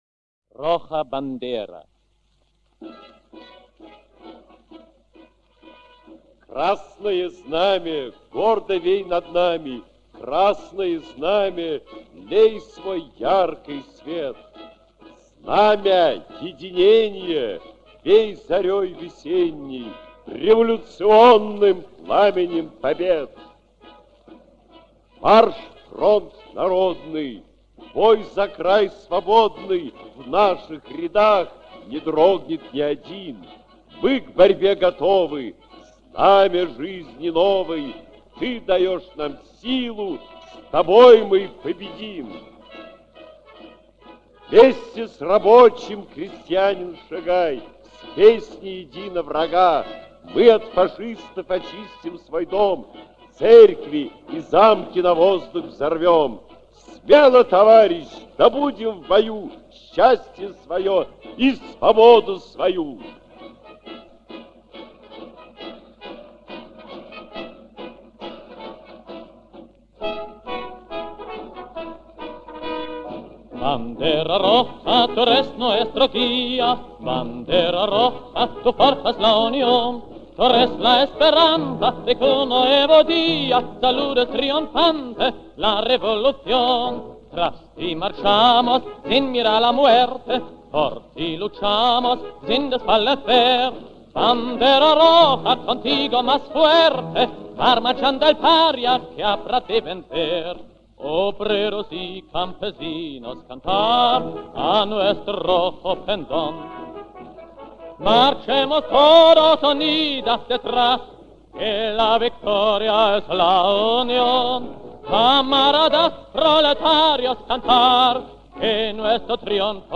Революционная испанская песня 1934 г.